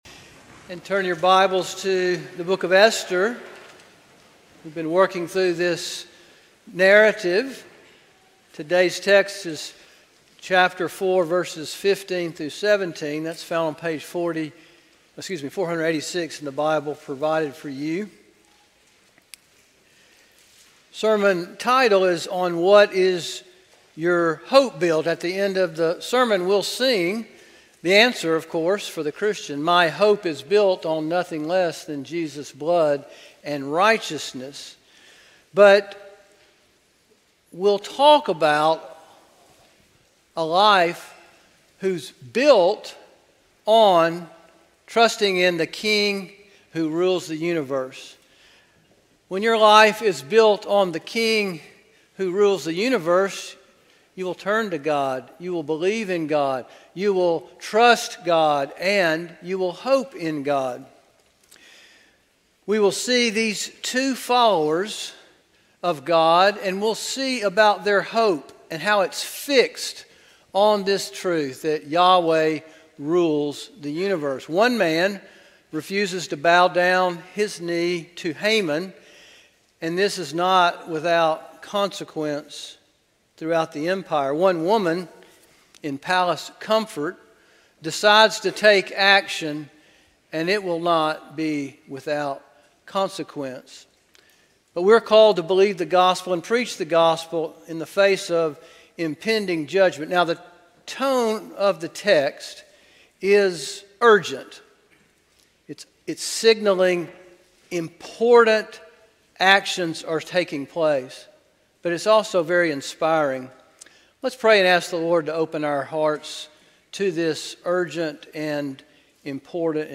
A sermon from the series "Esther."